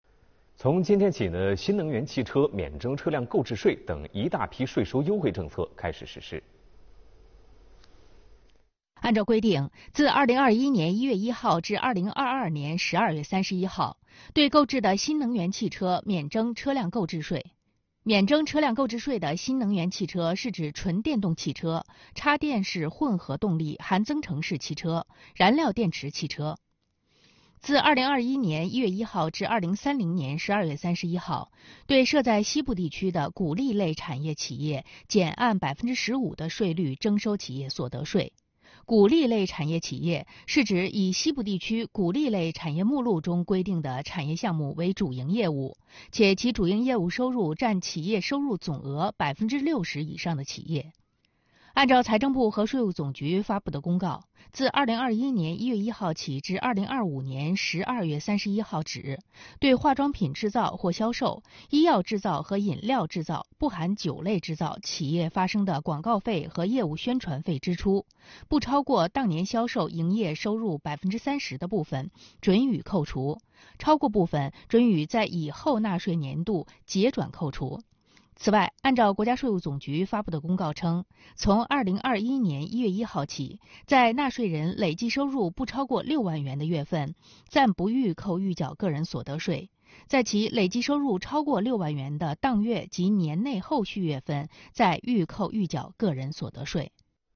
视频来源：央视《新闻直播间》